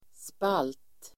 Uttal: [spal:t]